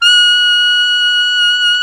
Index of /90_sSampleCDs/Roland L-CDX-03 Disk 1/SAX_Alto 414/SAX_Alto mp 414
SAX ALTOMP0S.wav